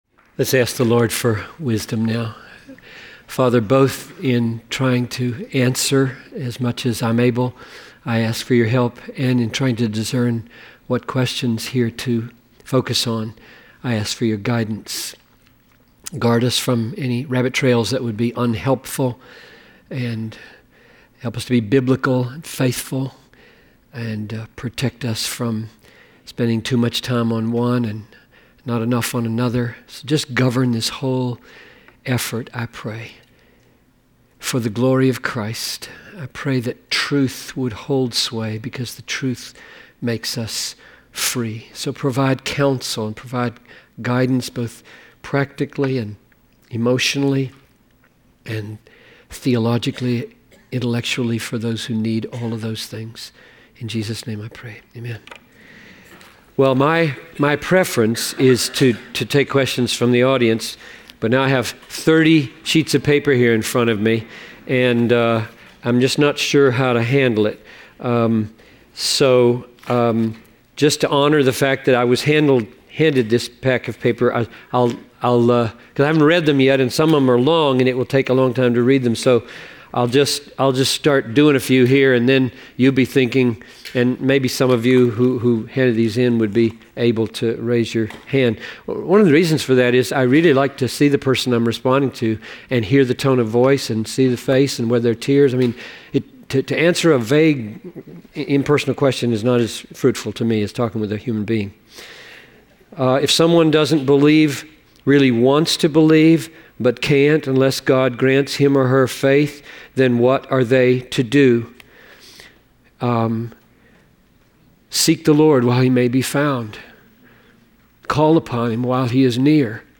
Q&A on Romans 8